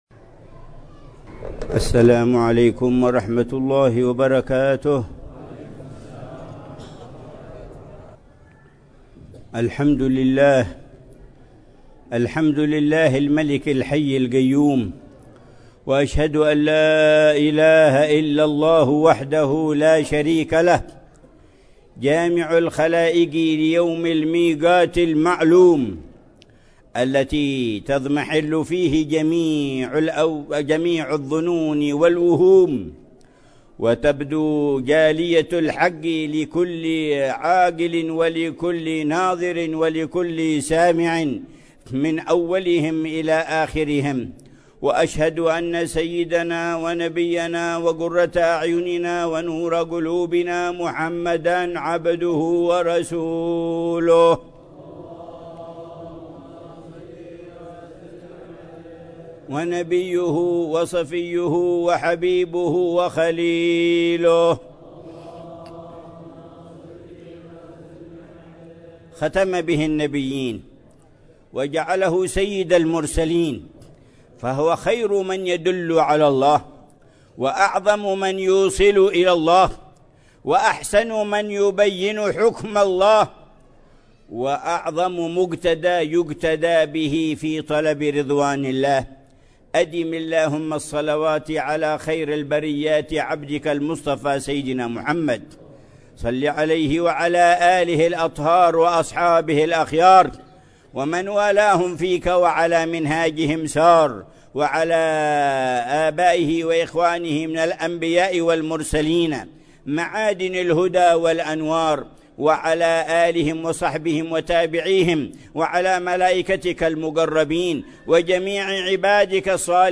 خطبة الجمعة
في جامع الخريبة، في مدينة الخريبة، بوادي دوعن، حضرموت